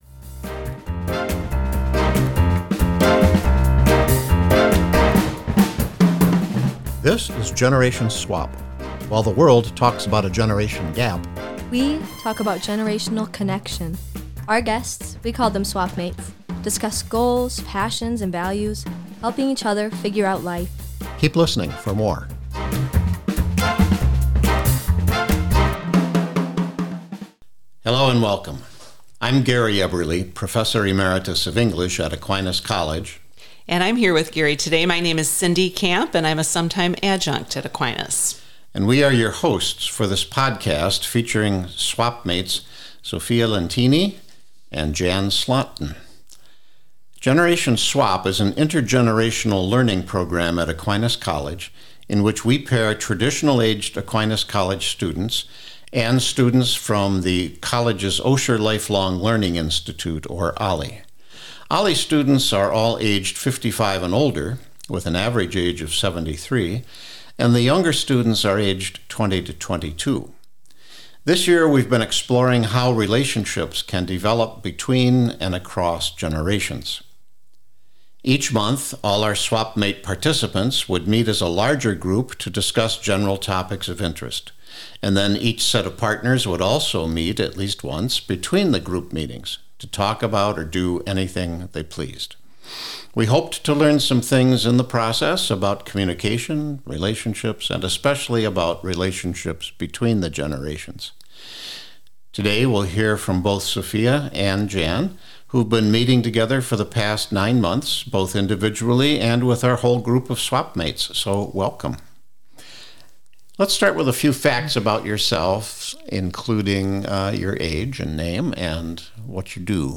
The course capstone project was a Generation Swap podcast interview exploring how generational differences can be a source of strength and growth.